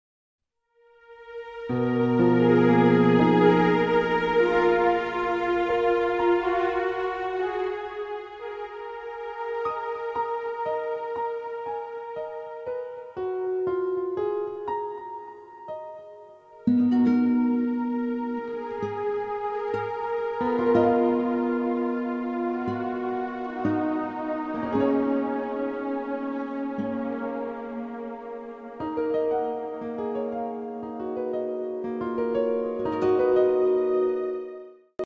Sound Track
SYNTHESIZER
笛
二胡